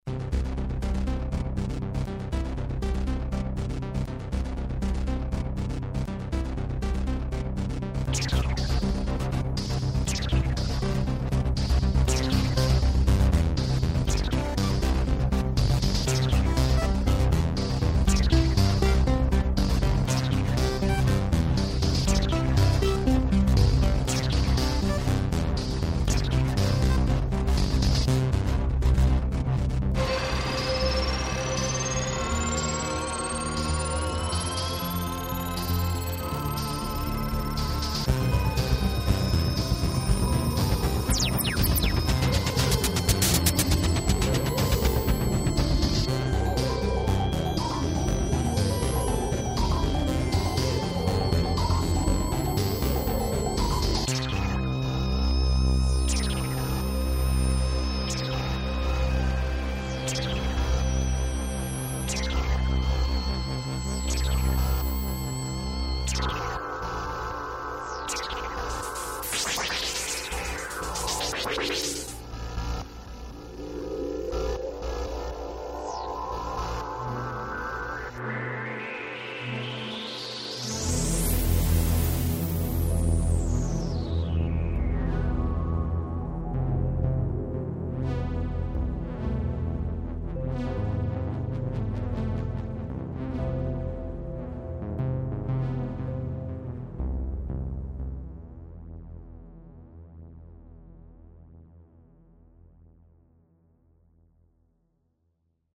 Waveform Synthesizer (1985)
demo DW8000 + drums Korg DRM1